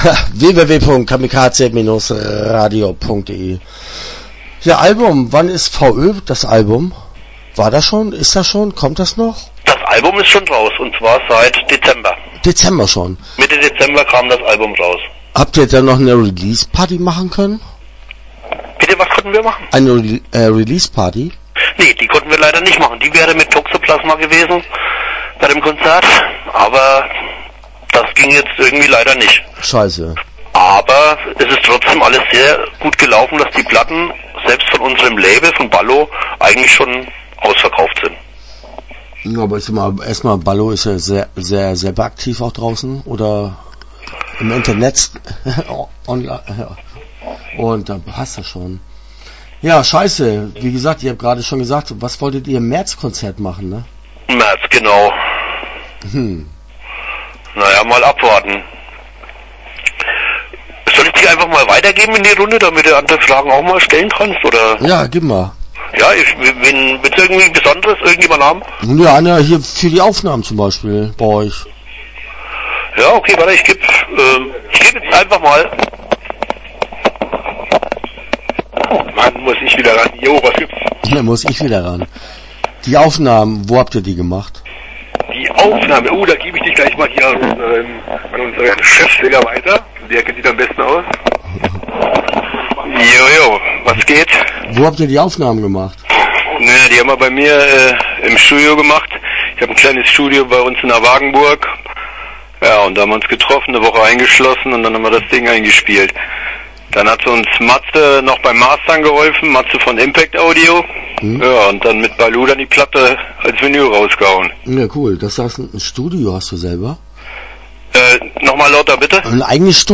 Frontalangriff - Interview Teil 1 (10:32)